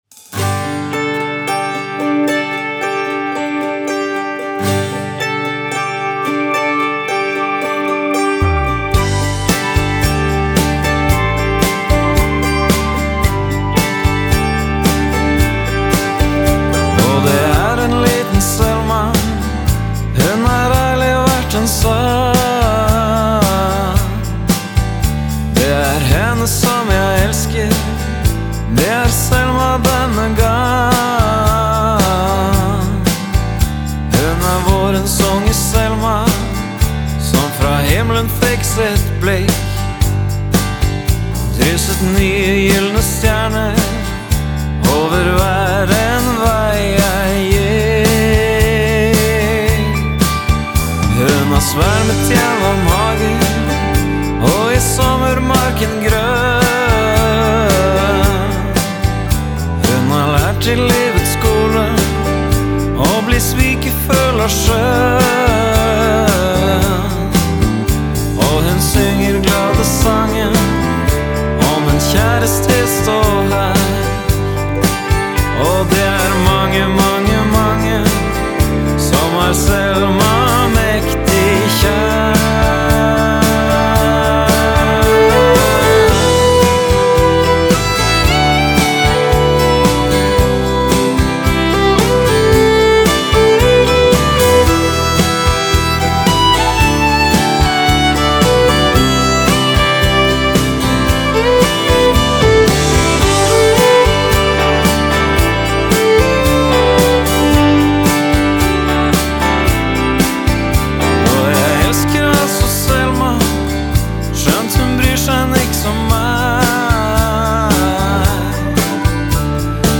folkrockbandet